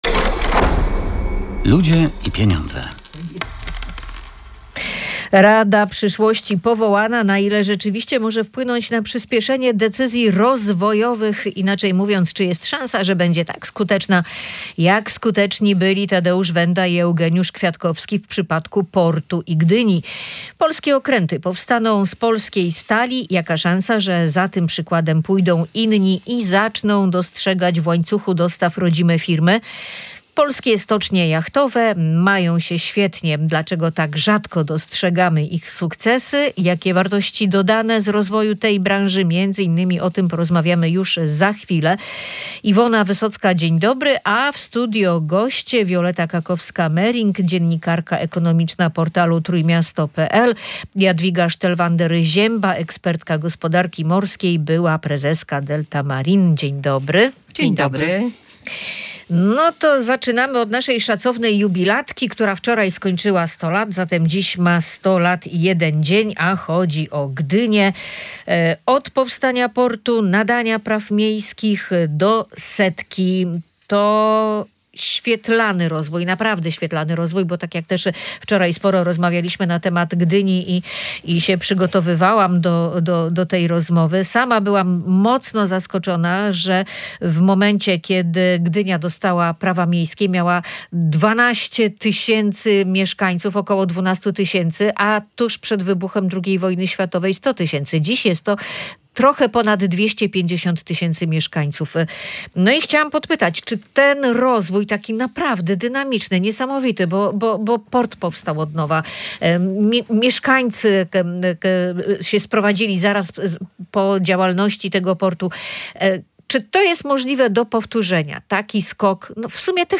Ma opracować rozwiązania przyśpieszające rozwój polskiej gospodarki i przetworzyć je w biznes. Premier powołał wczoraj Radę Przyszłości, do której weszli naukowcy i przedsiębiorcy. Między innymi na ten temat dyskutowali goście